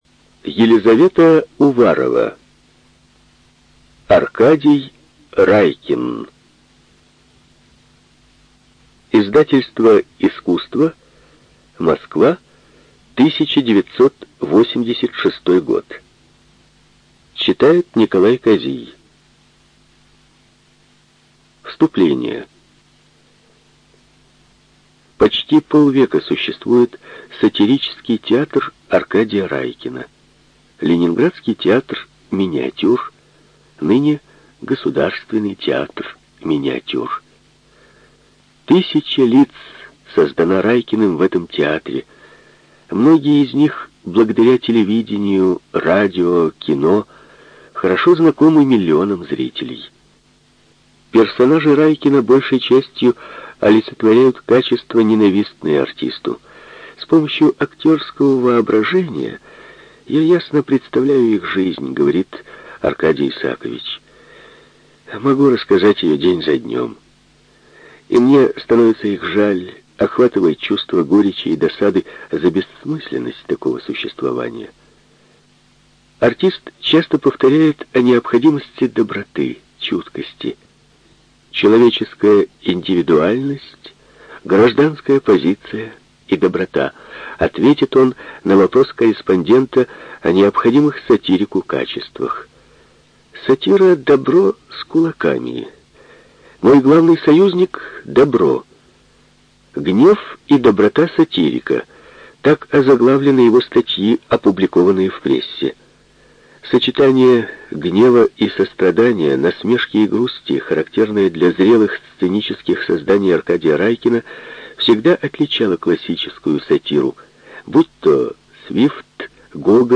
ЖанрБиографии и мемуары
Студия звукозаписиРеспубликанский дом звукозаписи и печати УТОС